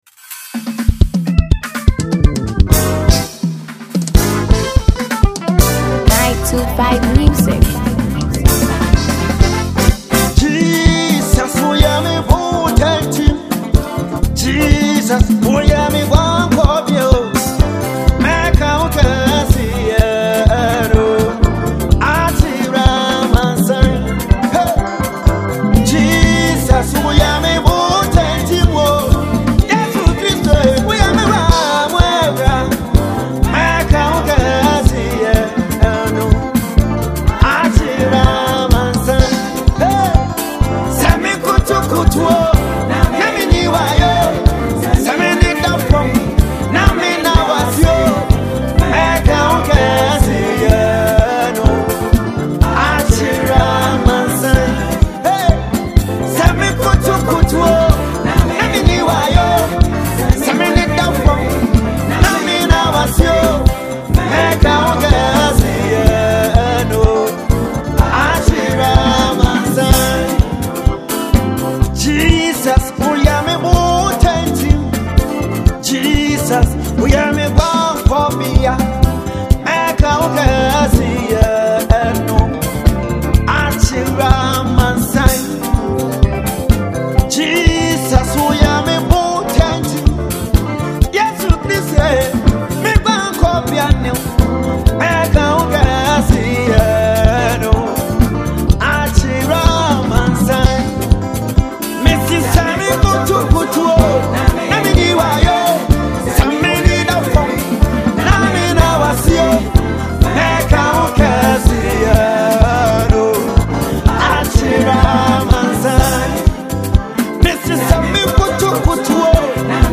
gospel artiste
praise medley
gospel tune